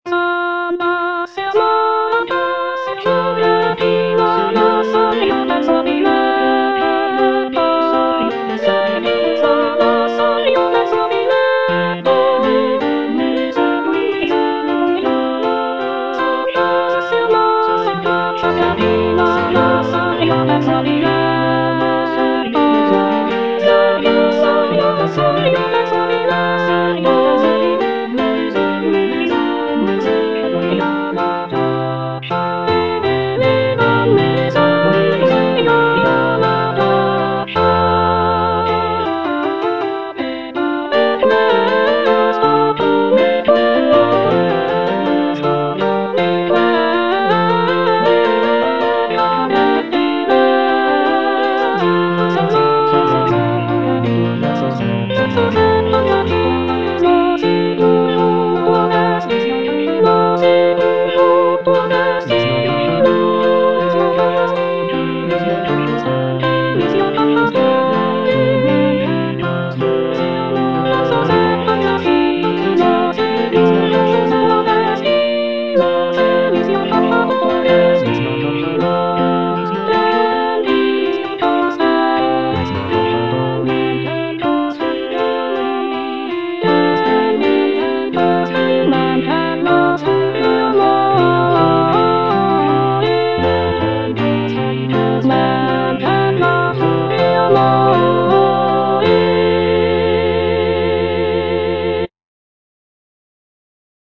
C. MONTEVERDI - S'ANDASSE AMOR A CACCIA Soprano I (Emphasised voice and other voices) Ads stop:
"S'andasse Amor a caccia" is a madrigal composed by Claudio Monteverdi, an Italian composer from the late Renaissance period.